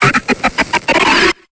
Cri d'Hippopotas dans Pokémon Épée et Bouclier.